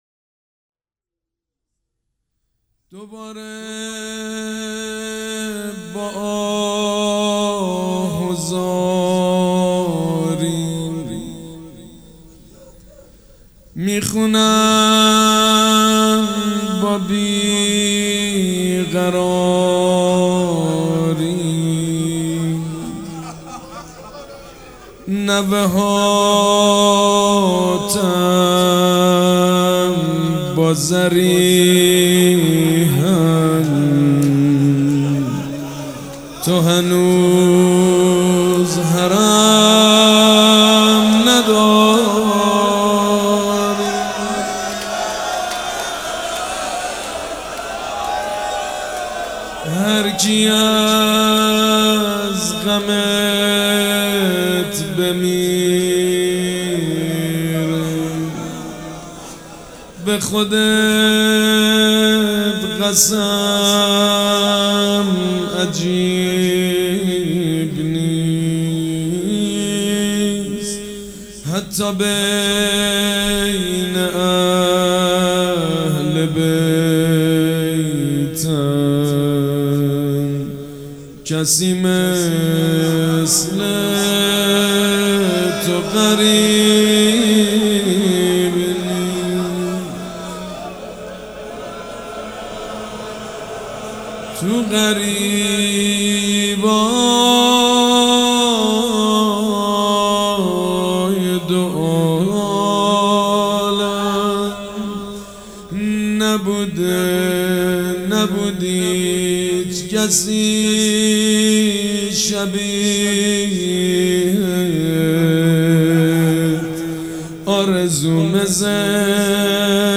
روضه
مداح
مراسم عزاداری شب پنجم